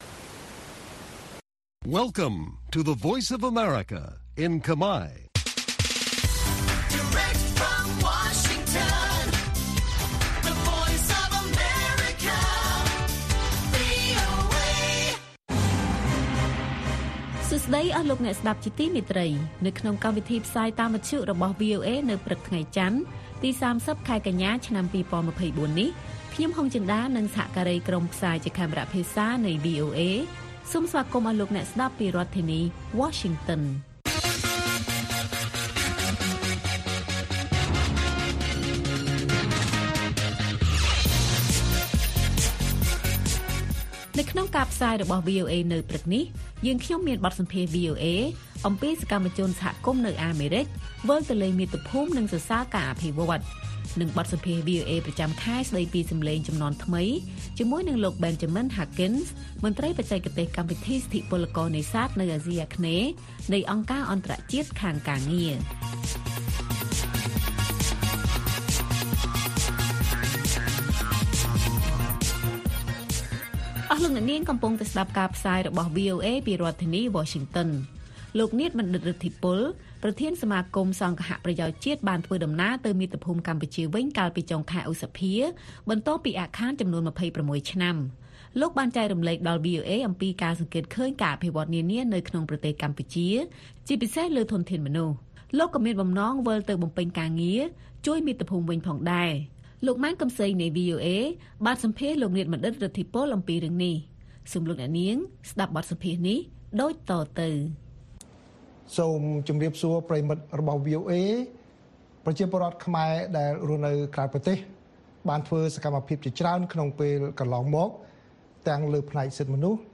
ព័ត៌មានពេលព្រឹក ៣០ កញ្ញា៖ បទសម្ភាសន៍ VOA អំពីសកម្មជនសហគមន៍នៅអាមេរិកវិលទៅលេងមាតុភូមិនិងសរសើរការអភិវឌ្ឍ
ព័ត៌មាននៅថ្ងៃនេះមានដូចជា បទសម្ភាសន៍ VOA អំពីសកម្មជនសហគមន៍នៅអាមេរិកវិលទៅលេងមាតុភូមិនិងសរសើរការអភិវឌ្ឍ។